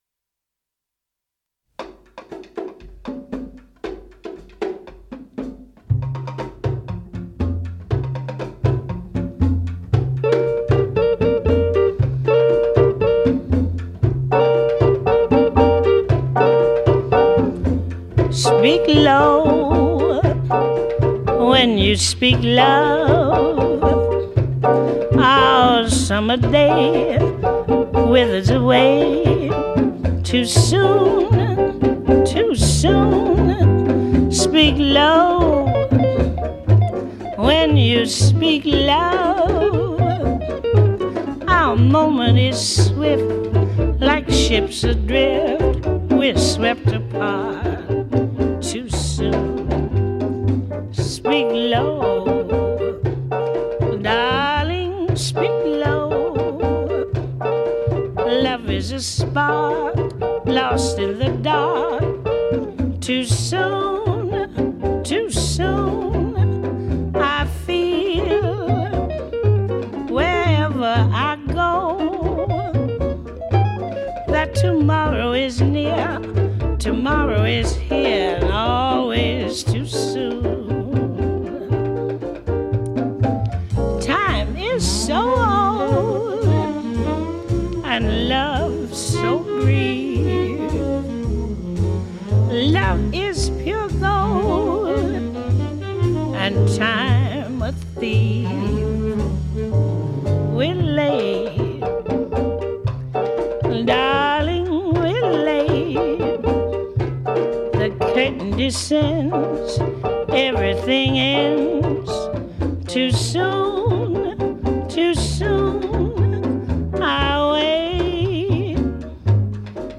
al pianoforte
Saluti finali